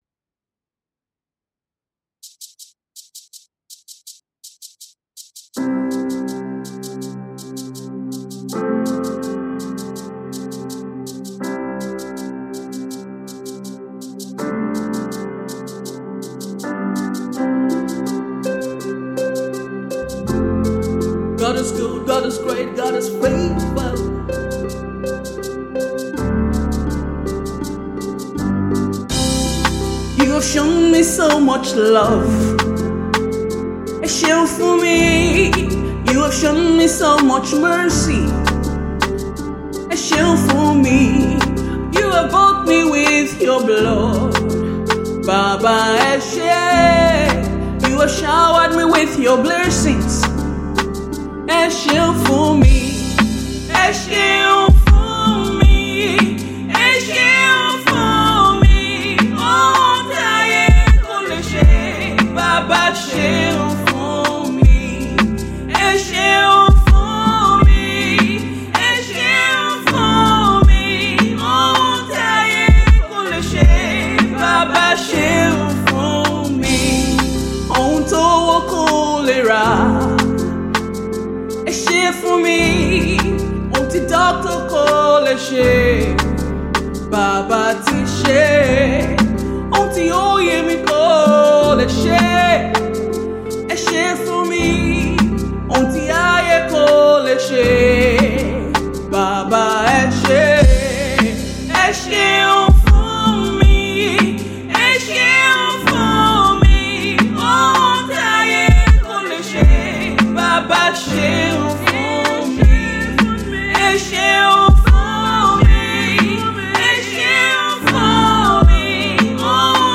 is a song of deep meditation and thanksgiving to God.
It is good for soul-lifting and worship.